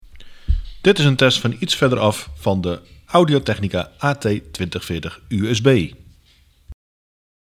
Verder van de mond af
De helderheid bij beide scenario’s is goed, de stem is helder en natuurgetrouw, al horen we wel duidelijk dat er meer warmte in de opname zit zodra je met je mond dichter bij de microfoon komt.
Bij de opname van iets verder van de mond af horen we ook iets meer geluid vanuit de achtergrond, omdat de microfoon een groter bereik moet gebruiken om alles op te nemen.
AT2040USB-afstandopname.mp3